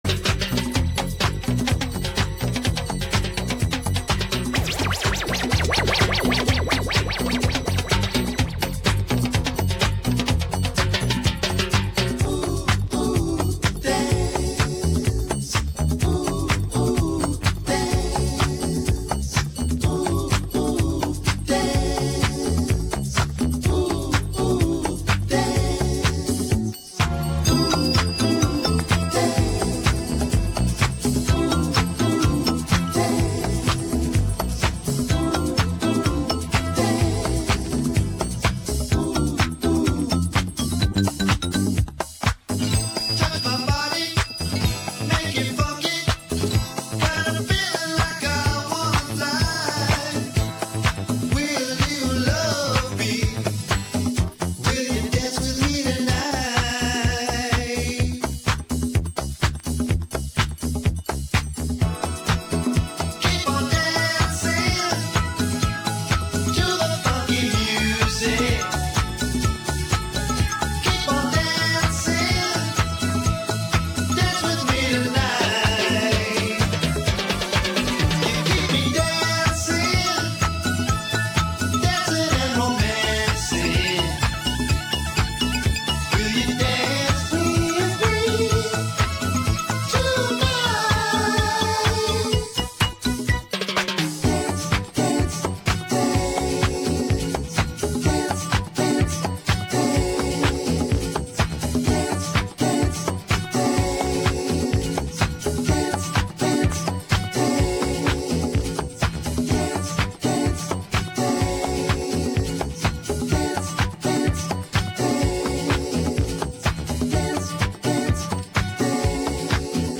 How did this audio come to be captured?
recorded in an Italian club called